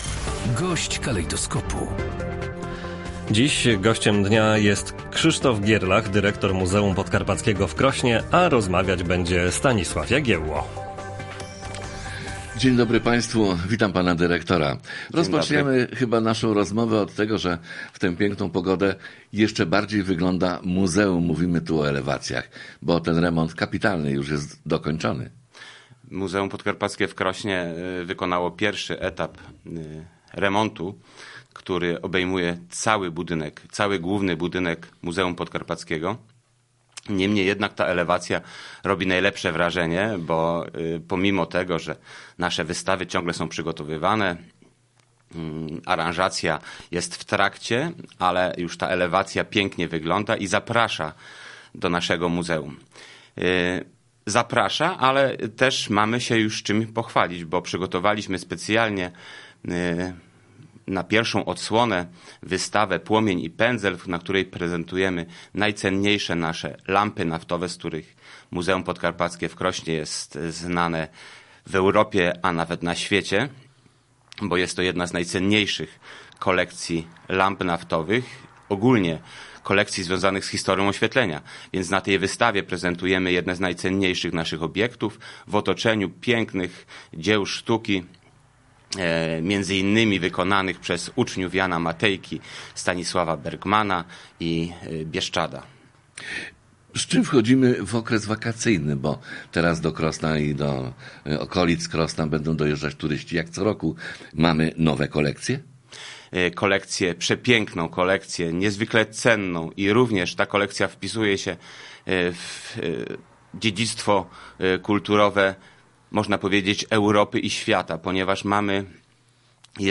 Gość dnia.